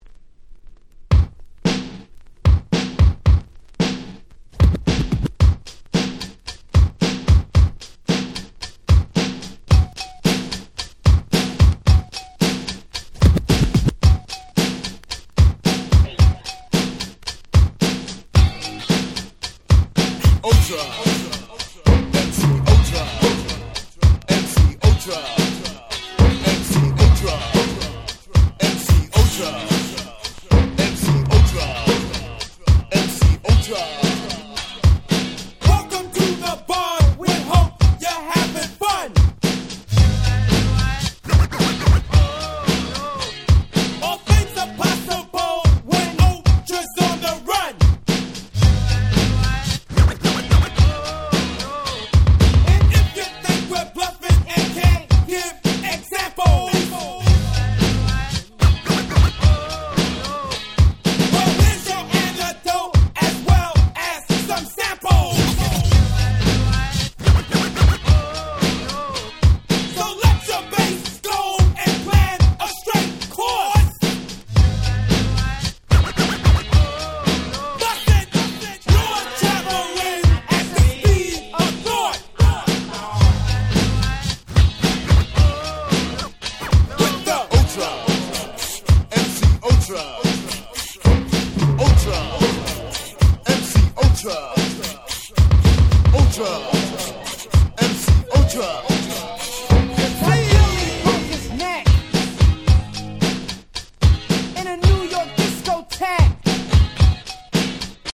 87' Hip Hop Classics !!
BPM速めでFunkyなBeatがクセになる超絶クラシック！！